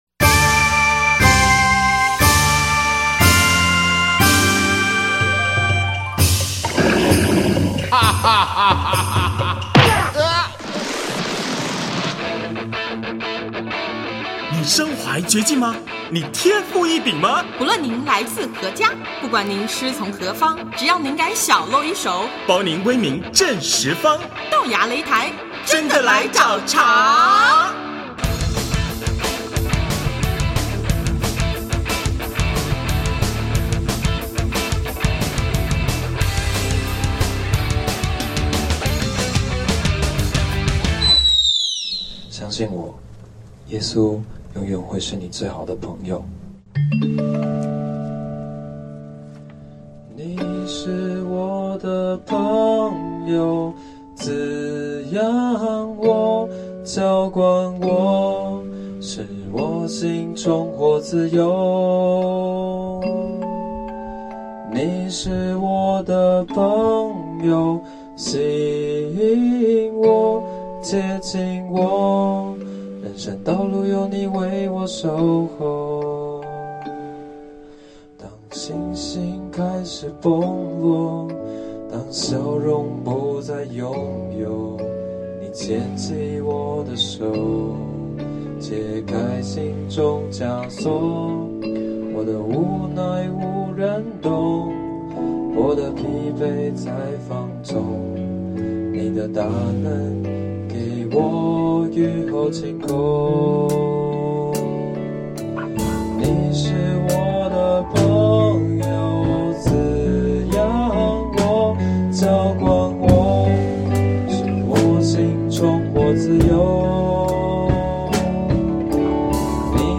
2011年在静修女中举行，分别有来自光仁中学、圣心女中…等十二所高中同学参与。活动中，同学们各自以音乐、戏剧、互动祈祷…等方式让与会者感受到~认识主耶稣后，我们不再孤单，还能结交更多的好朋友！